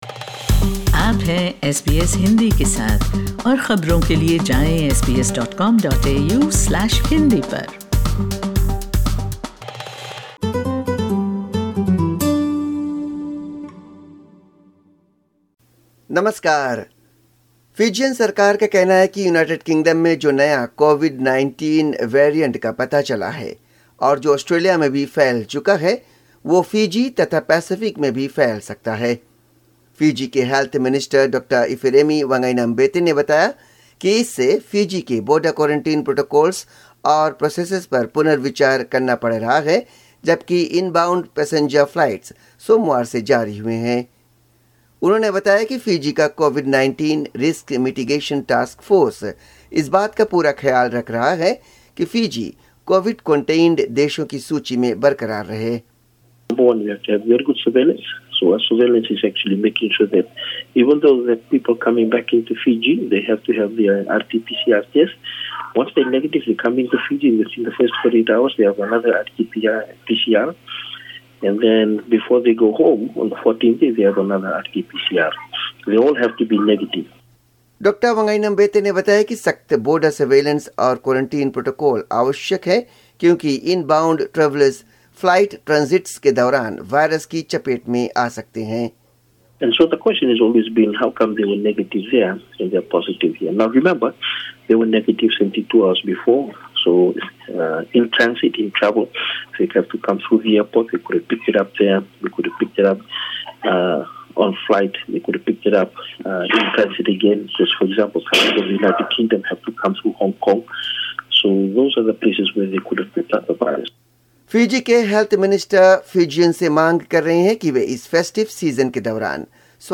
Fiji News in Hindi